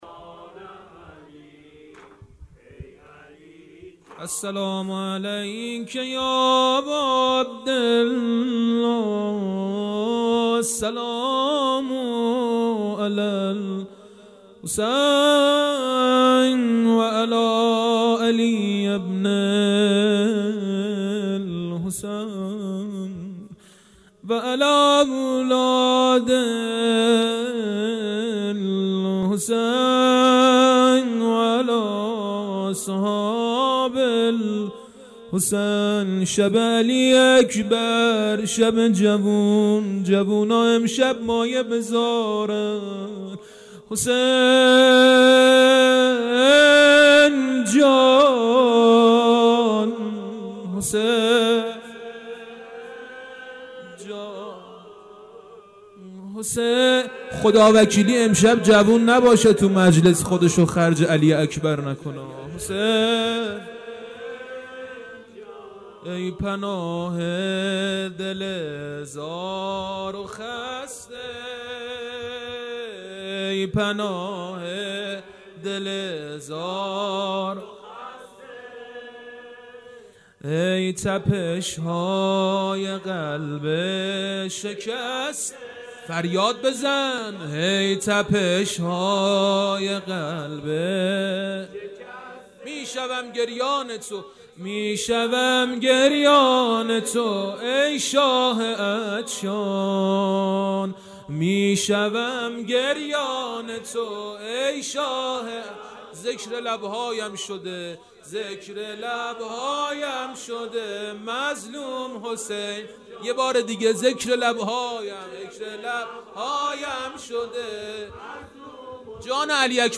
خیمه گاه - هیئت حضرت رقیه س (نازی آباد) - شب هشتم مداحی
محرم سال 1398